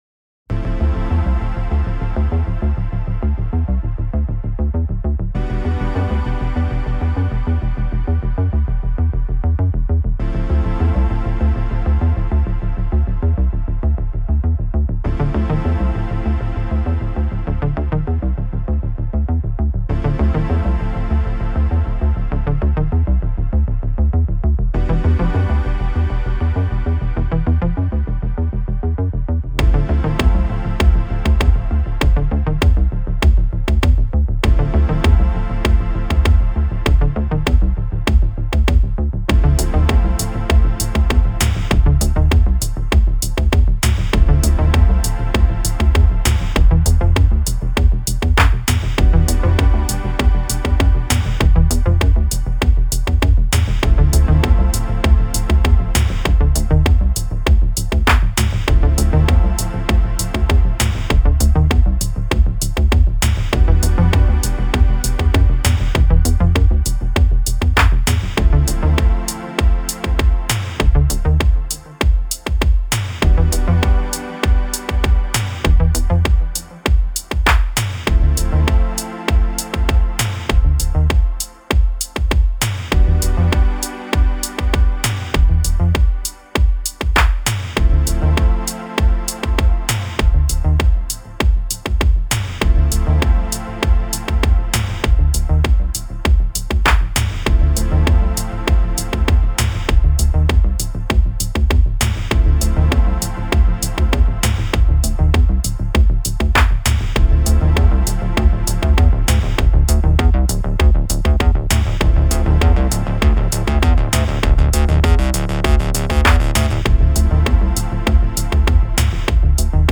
Ba, Ba, Bass kann'er jut - der Kleine Scheißer (obwohl hier der Minilogue zum Einsatz kam / OSC Sektion im Kern identisch) Nicht vergleichbar, da der...
Man merkt dem Bass in dem Audio übrigens, dass hier tatsächlich etwas wenig Punch vorliegt und der Klangverlauf etwas flau ist - dann möglicherweise doch 12dB Flankensteilheit ?